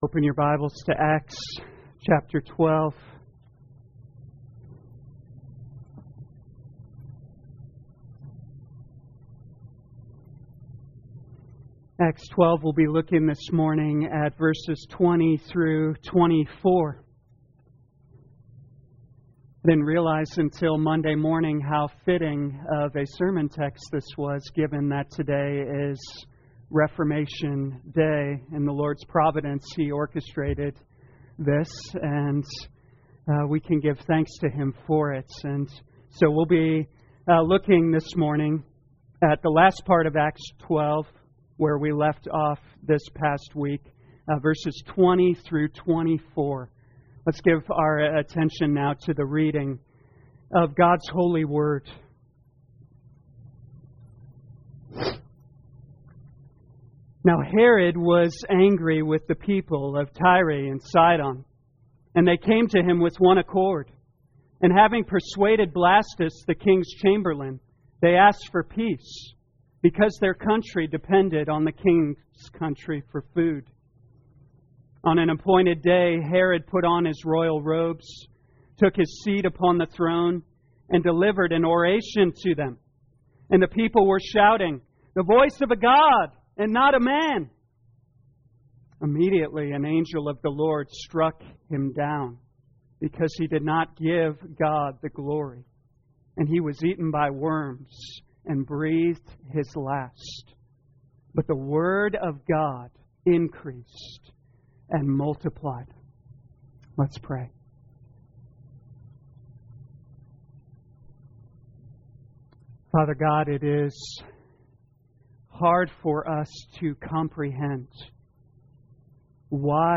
2021 Acts Morning Service Download